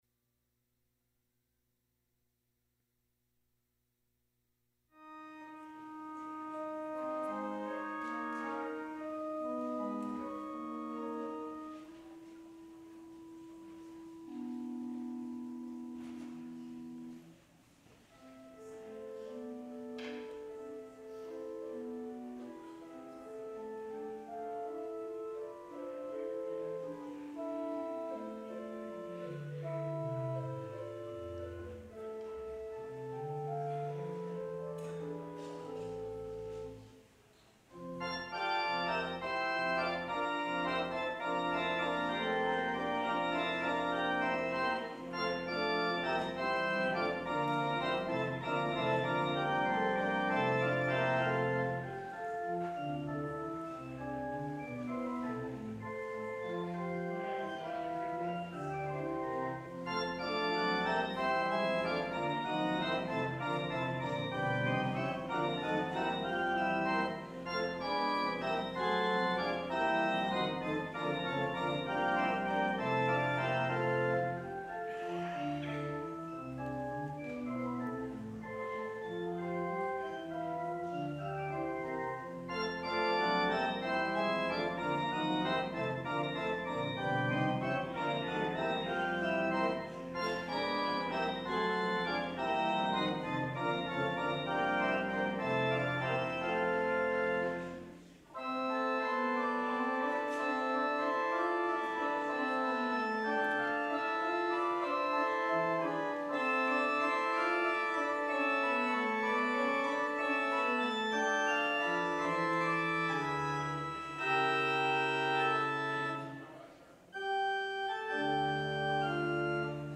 Traditional Sermon